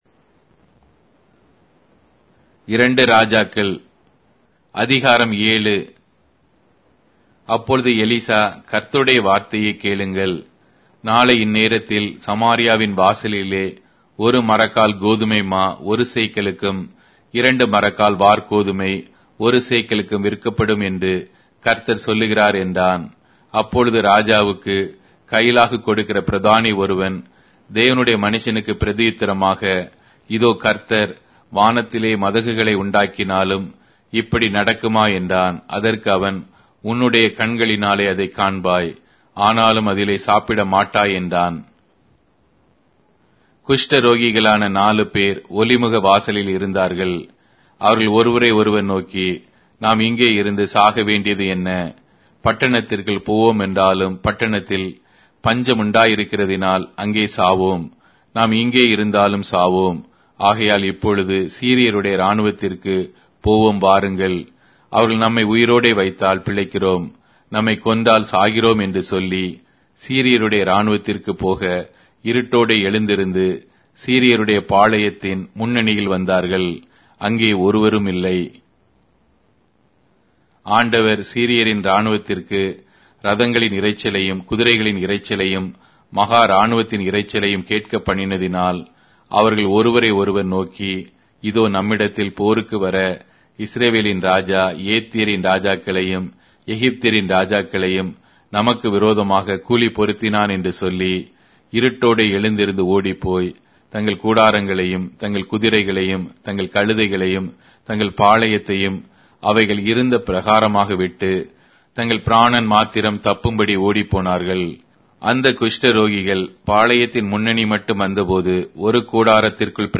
Tamil Audio Bible - 2-Kings 16 in Hov bible version